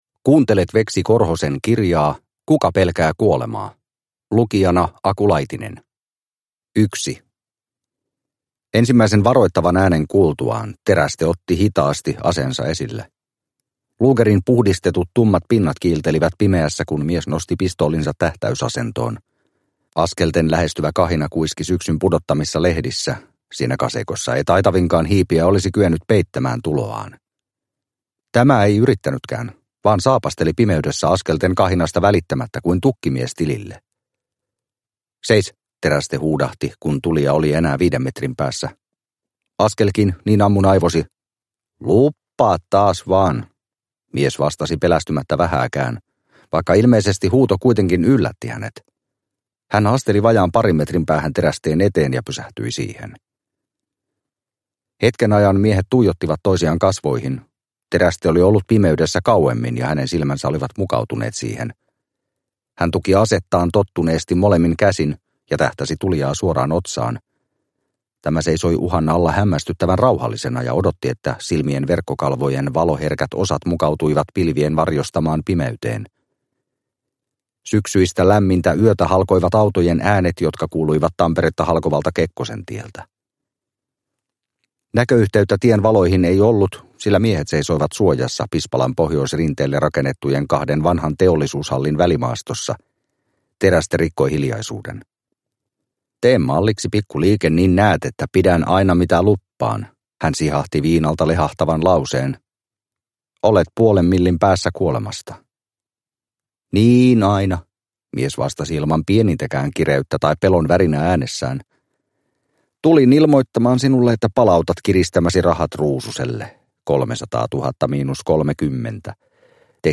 Kuka pelkää kuolemaa – Ljudbok – Laddas ner